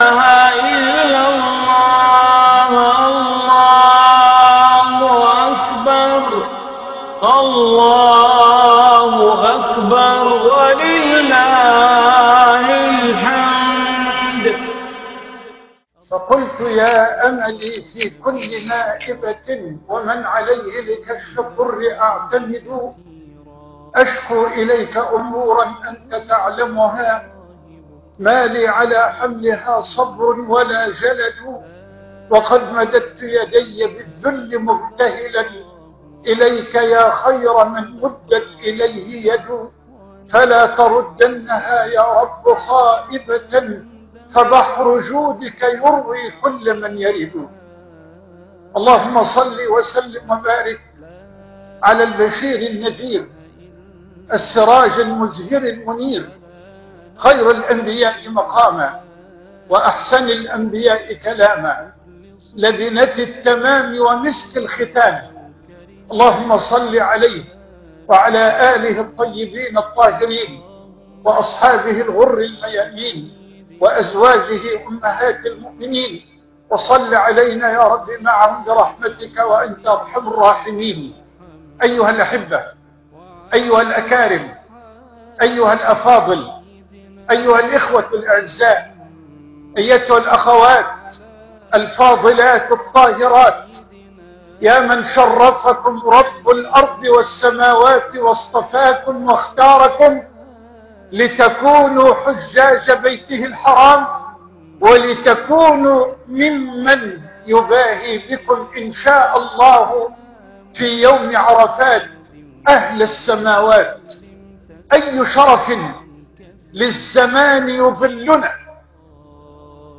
درس فجر يوم عرفة لحج عام 1446 هـ - فضيلة الشيخ محمد حسان